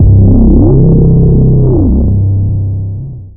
DEEDOTWILL 808 86.wav